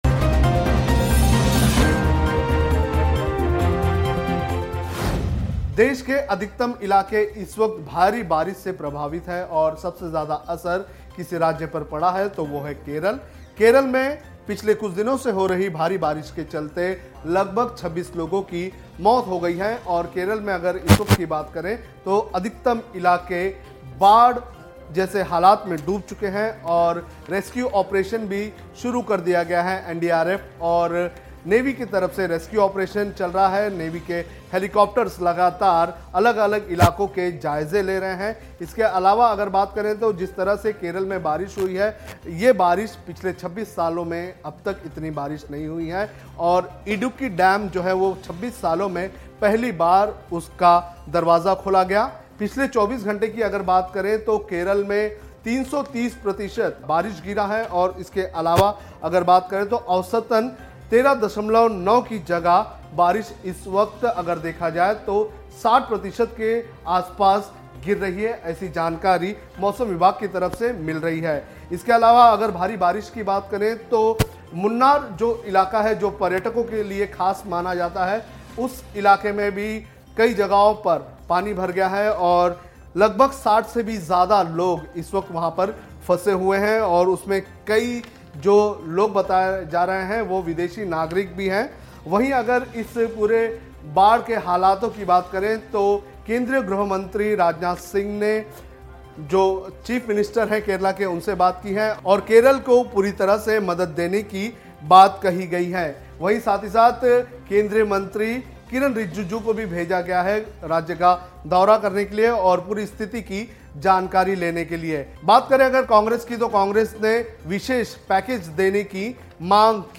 विशेष रिपोर्ट : केरल में तबाही का सैलाब , 26 लोगो की मौत